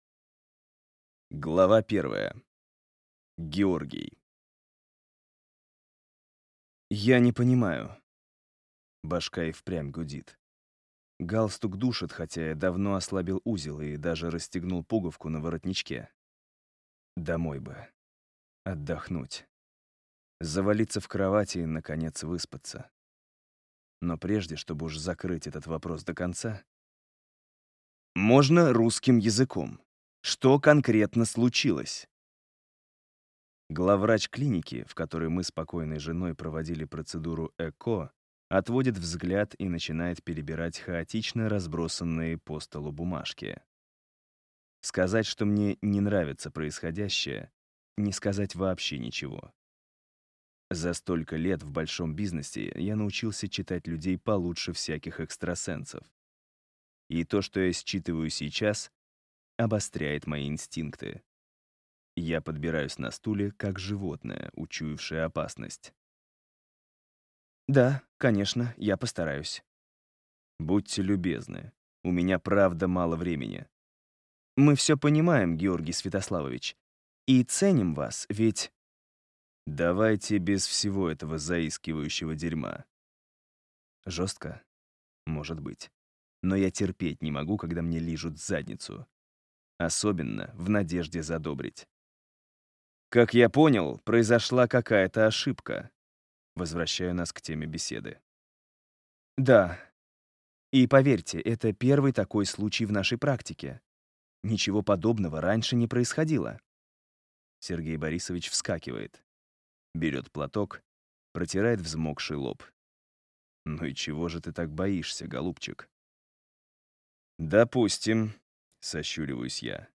Аудиокнига Моя по умолчанию | Библиотека аудиокниг